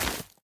Minecraft Version Minecraft Version latest Latest Release | Latest Snapshot latest / assets / minecraft / sounds / block / nether_sprouts / break3.ogg Compare With Compare With Latest Release | Latest Snapshot